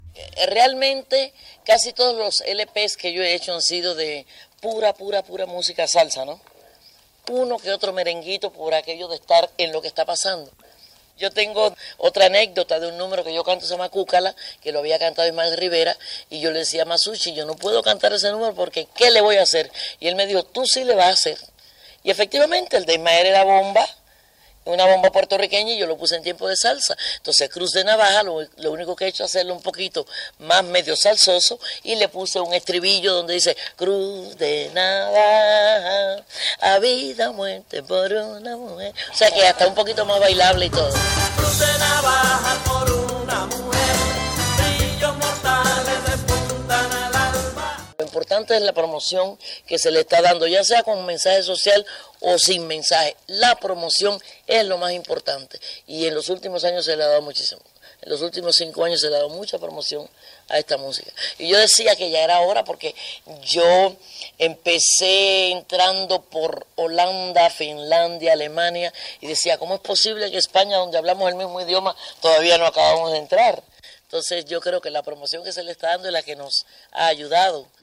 Entrevista a la cantant cubana Celia Cruz que presentava el seu disc "Azúcar negra"